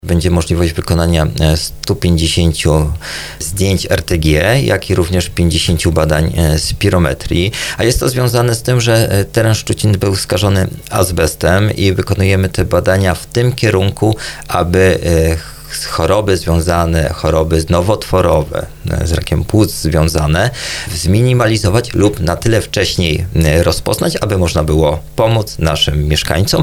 Burmistrz Szczucina Tomasz Bełzowski zachęcał na antenie Radia RDN Małopolska, by skorzystać z badań.